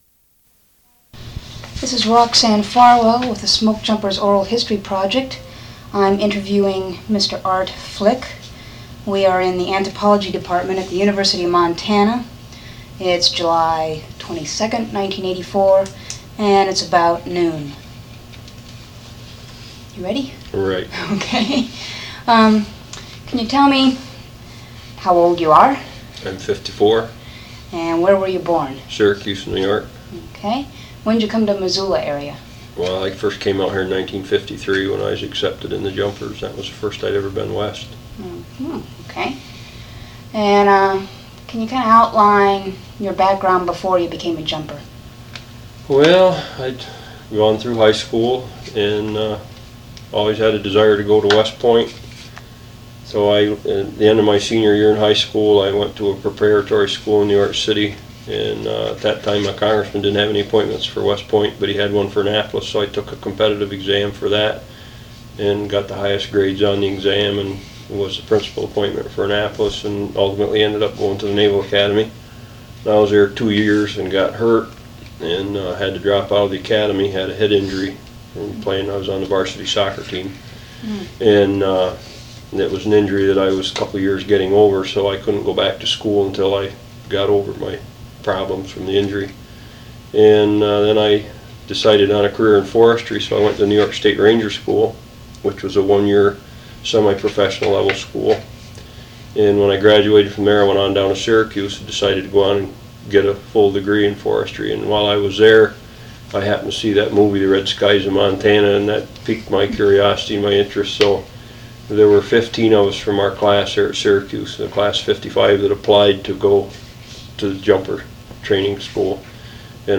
Document Type Oral History
Original Format 1 sound cassette (58 min.) : analog